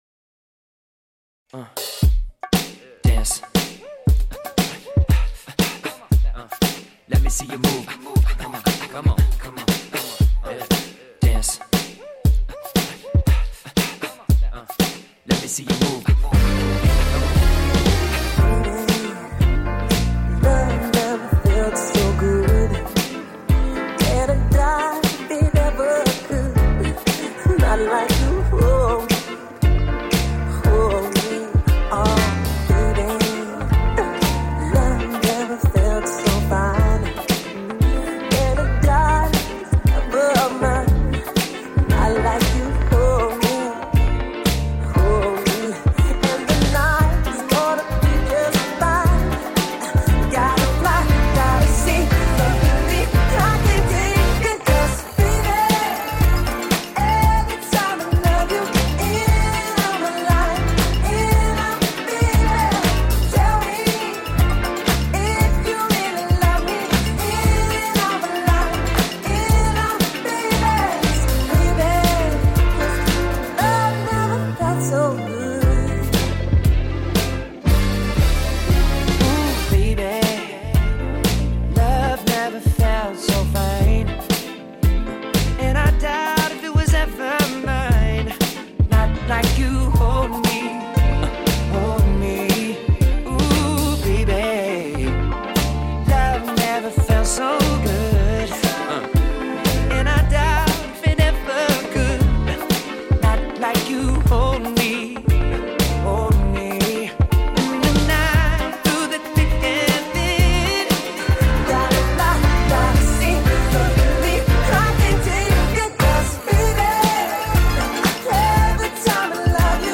Pop, R&B, Funk